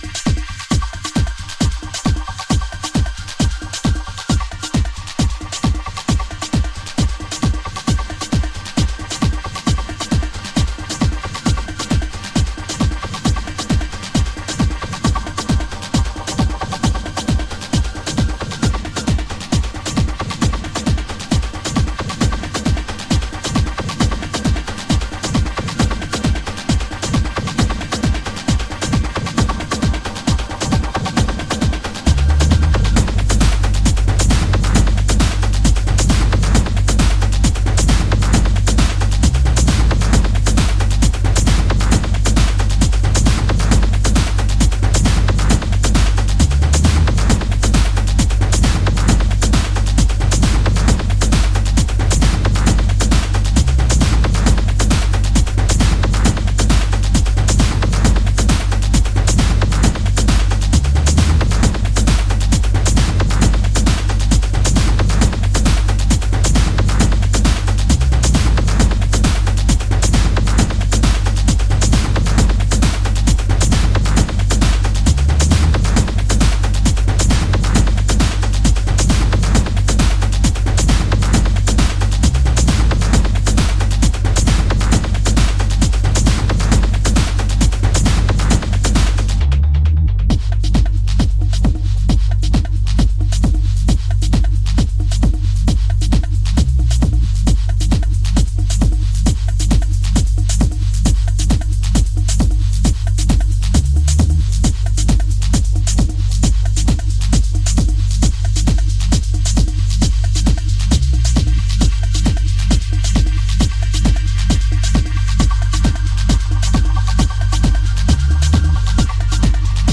Early House / 90's Techno